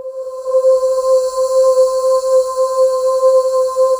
Index of /90_sSampleCDs/USB Soundscan vol.28 - Choir Acoustic & Synth [AKAI] 1CD/Partition C/07-DEEEP